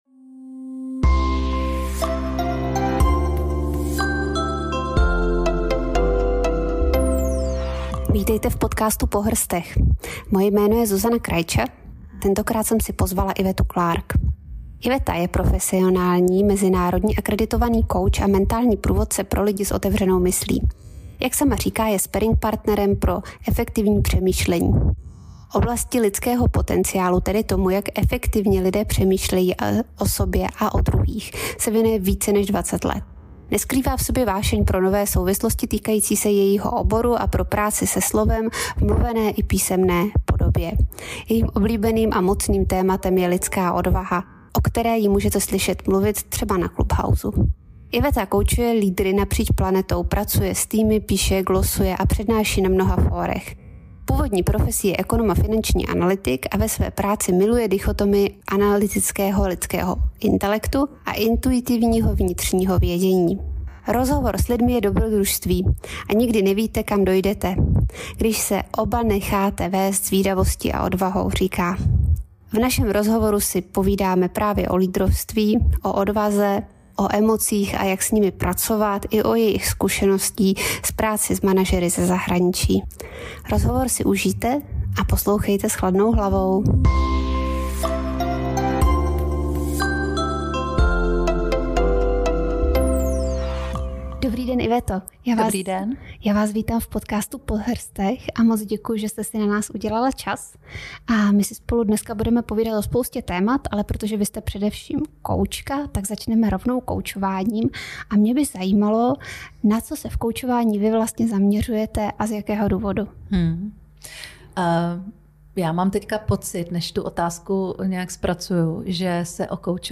V našem rozhovoru si povídáme o emocích a jak s nimi pracovat nebo o jejích zkušenostech s managery ze zahraničí.